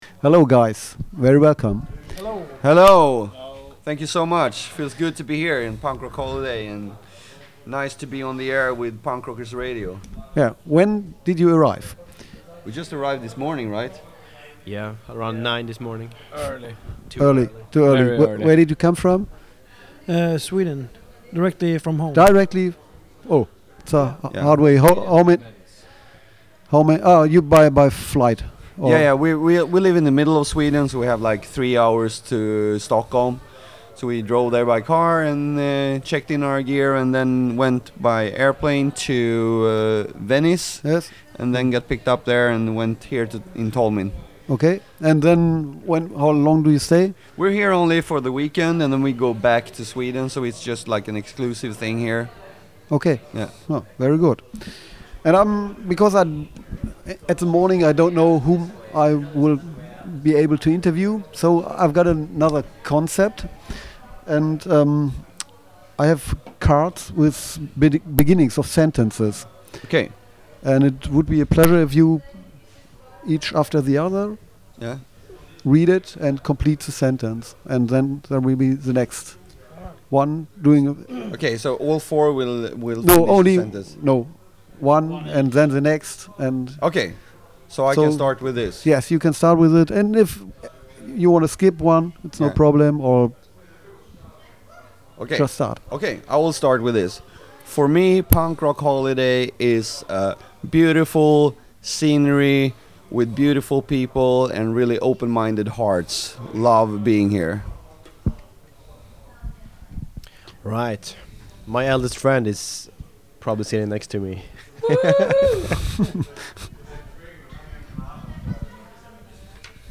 Letzte Episode Misconduct Interview @ Punk Rock Holiday 1.8 8. August 2018 Nächste Episode download Beschreibung Teilen Abonnieren Before Swedish punkrockers Misconduct entered the main stage at Punk Rock Holiday, they visited us in our studio for an interview.
misconduct-interview-punk-rock-holiday-1-8-mmp.mp3